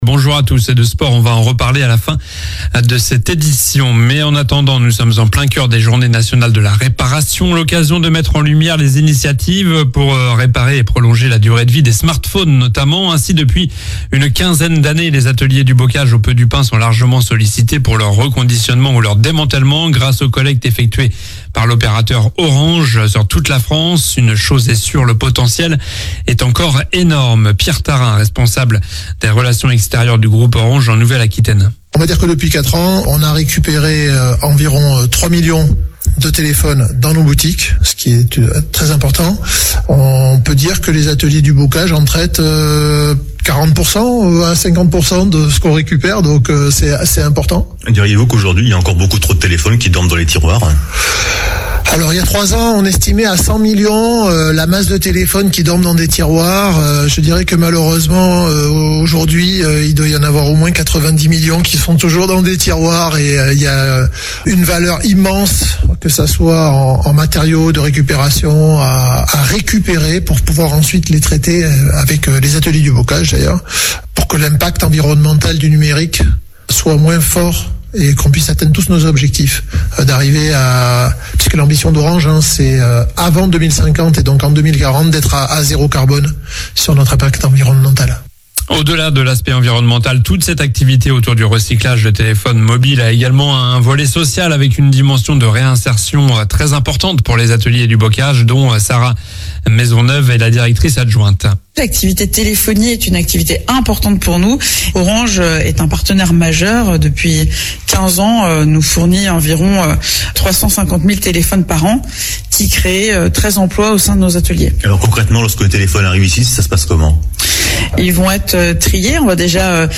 Journal du samedi 19 octobre (matin)